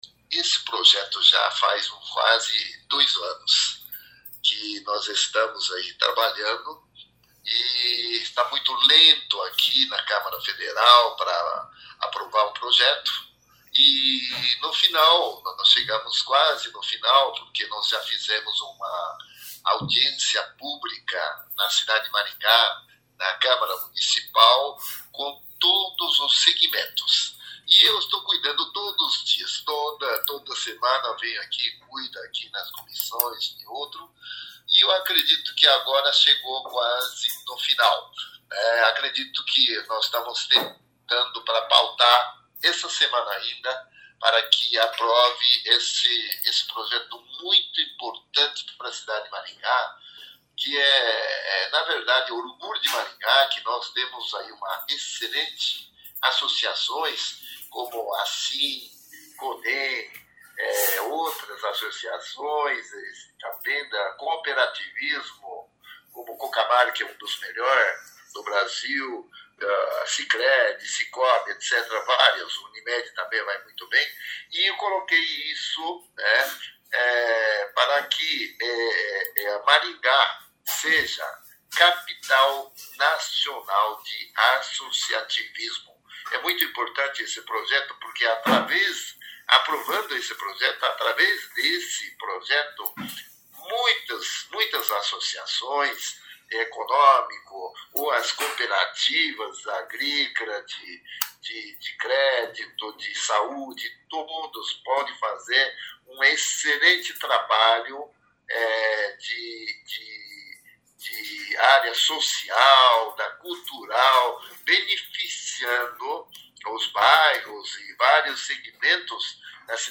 O deputado fala também sobre outros temas polêmicos: PEC da blindagem, foro privilegiado e anistia aos envolvidos no 8 de janeiro. Ouça a entrevista: